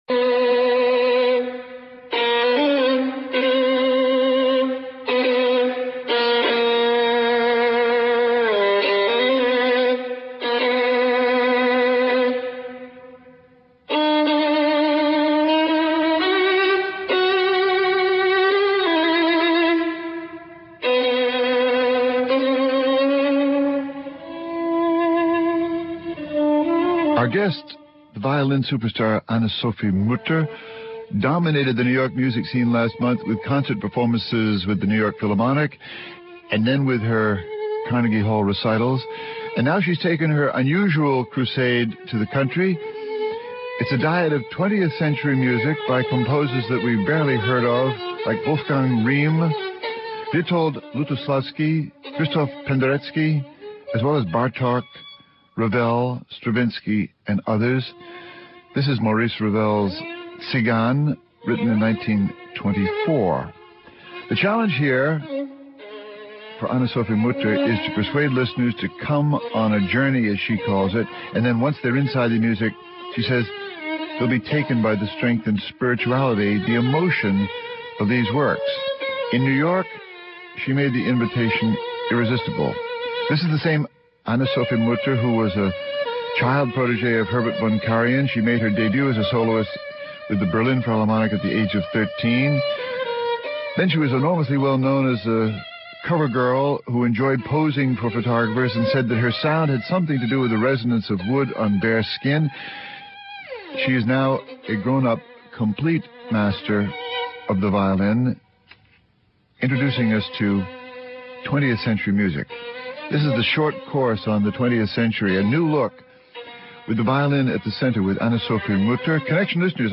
(Hosted by Christopher Lydon) Guests: Anne-Sophie Mutter, violinist.